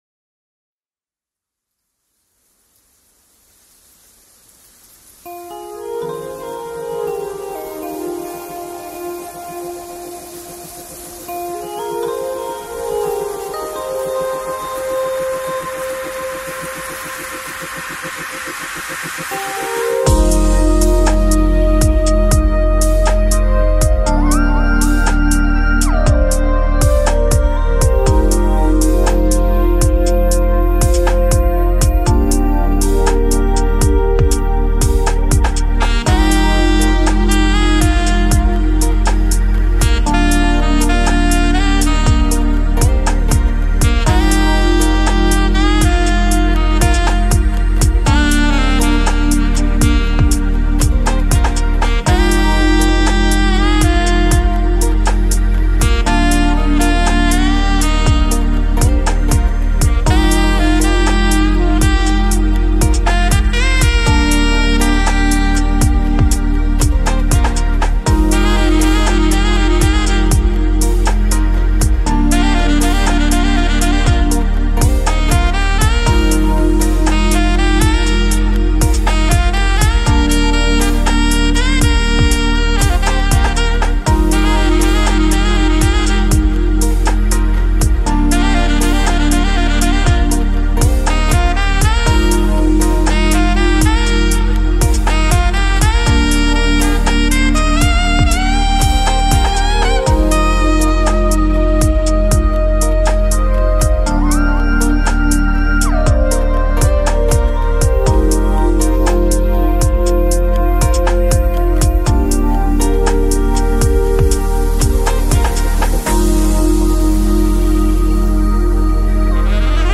materiales_1.pdf 🎼 Música de elevador para que escuches 🎼 Sonidos suaves para esperar.mp3